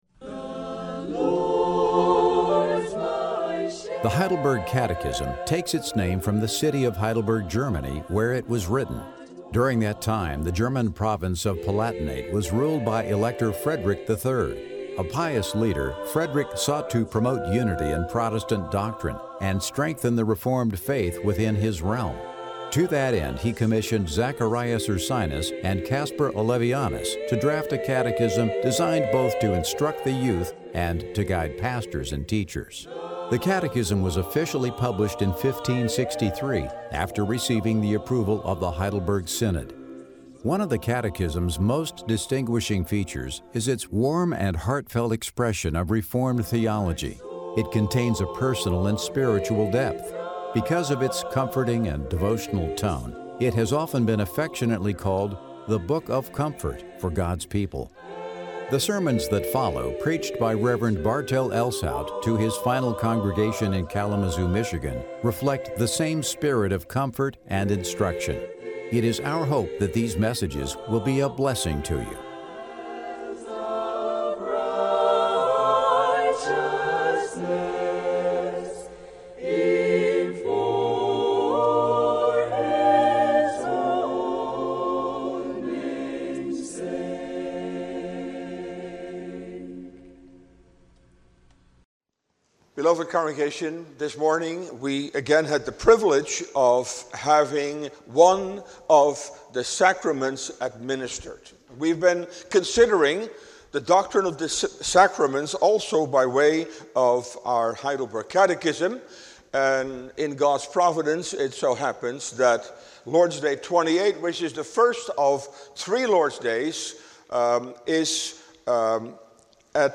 Sermon Downloads